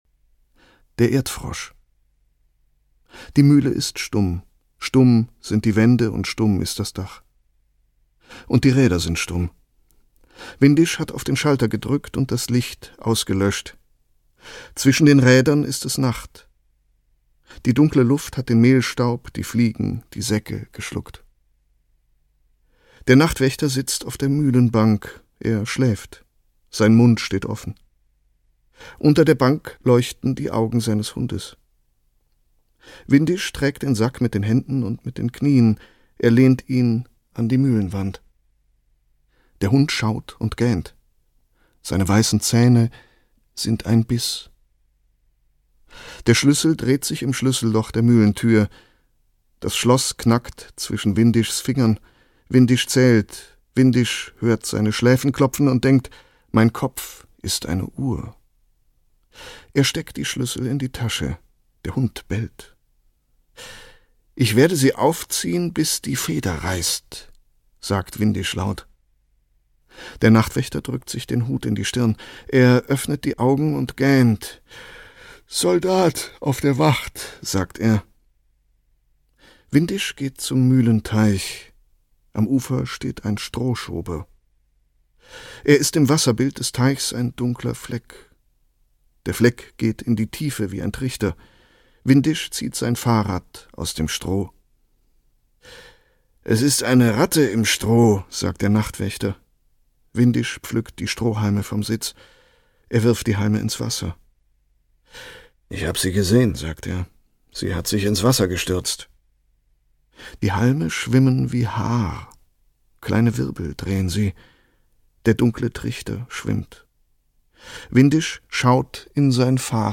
Der Mensch ist ein großer Fasan auf der Welt - Herta Müller - Hörbuch